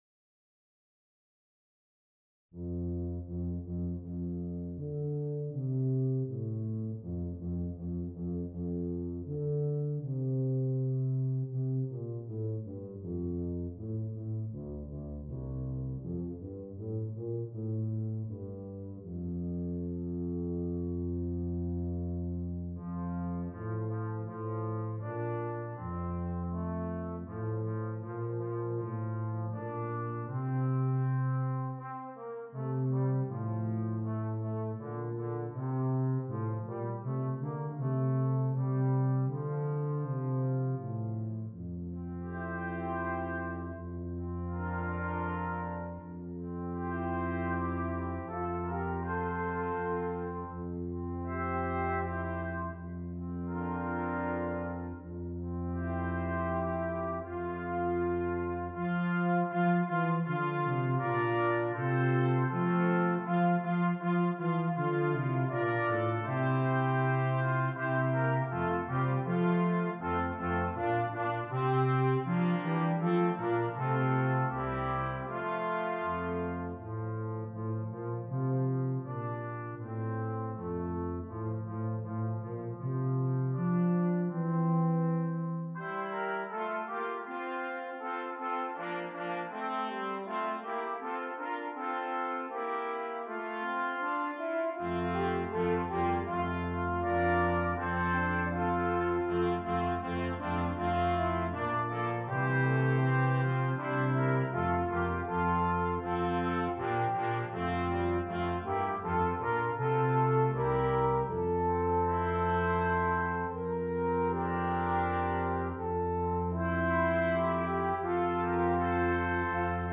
Brass Quintet
Traditional Carol
This piece is flowing and smooth, quiet and reflective.